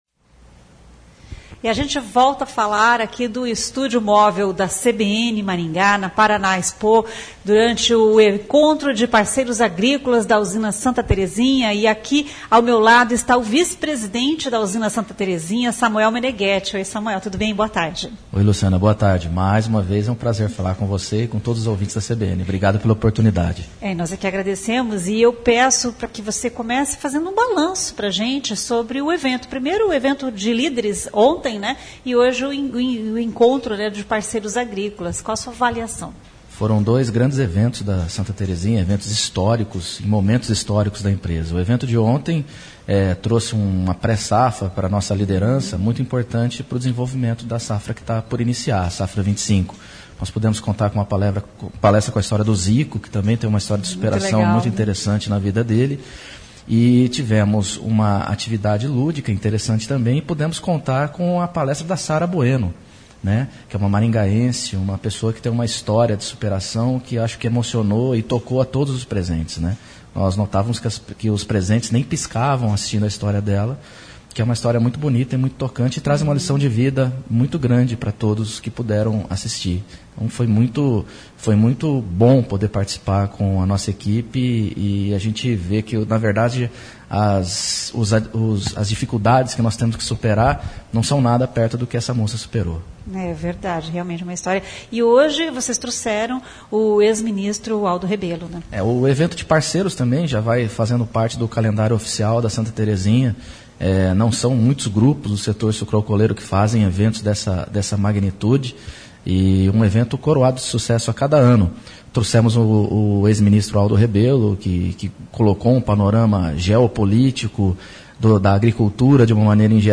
Estúdio Móvel CBN